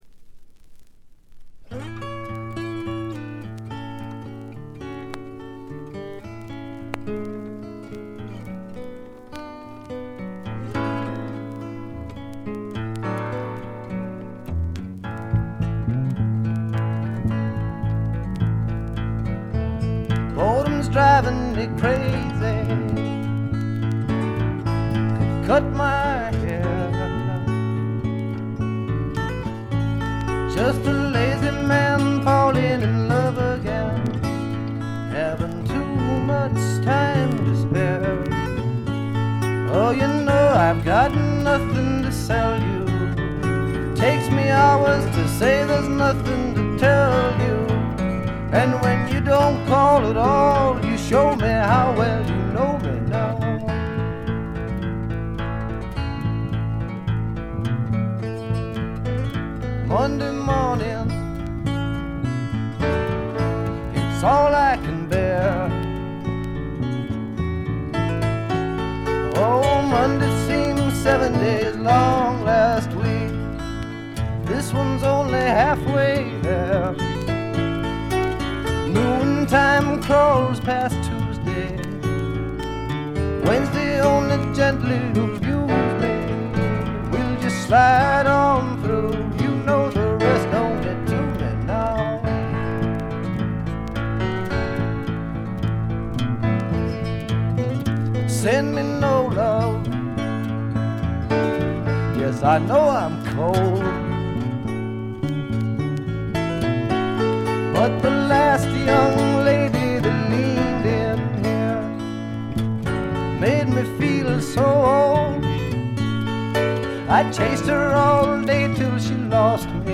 A5序盤、B2冒頭でプツ音。
試聴曲は現品からの取り込み音源です。